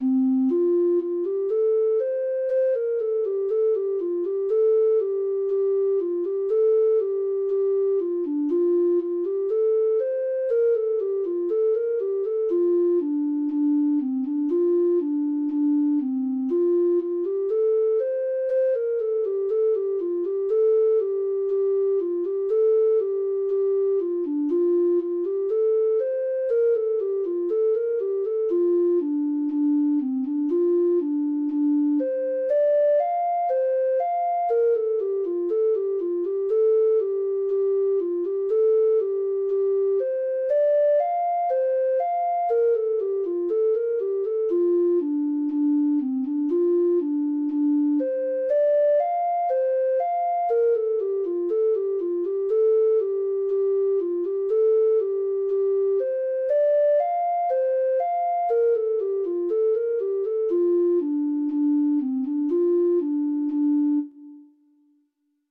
Free Sheet music for Treble Clef Instrument
Traditional Music of unknown author.
Irish